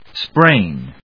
/spréɪn(米国英語), spreɪn(英国英語)/